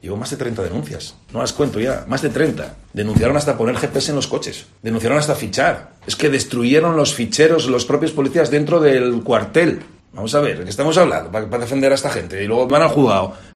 El alcalde de Siero habla de las denuncias que el SIPLA ha interpuesto contra él